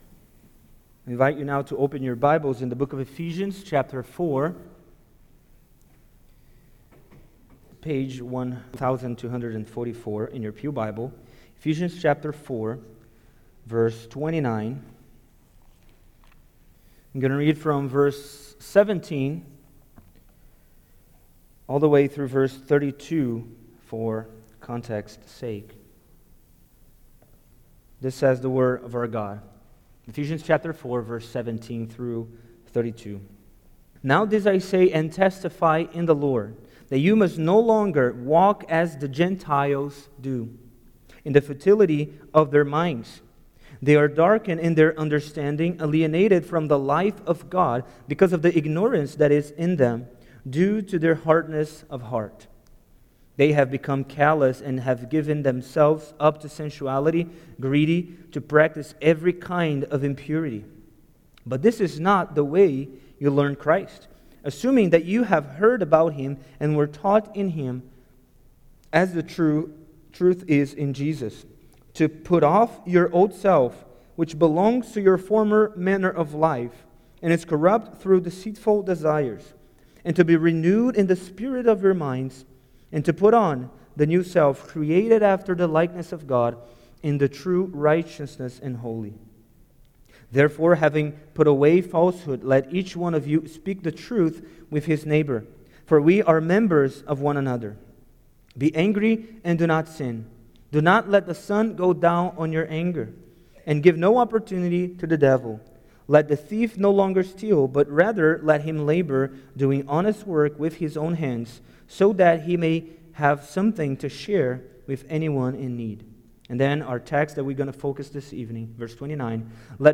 New Life Series Various Sermons Book Ephesians Watch Listen Save In Ephesians 4:17-32, the apostle Paul continues describing the new life in Christ and the expectation believers will build others up in truth and love.